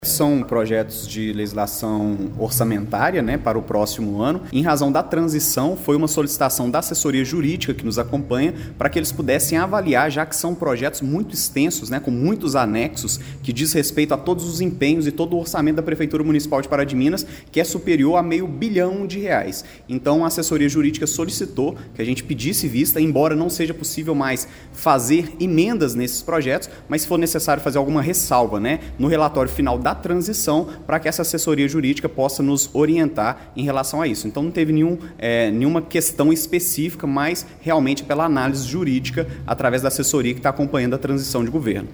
Ele será o vice-prefeito de Inácio Franco a partir do ano que vem e disse que não fez o pedido de vista por nenhuma questão específica.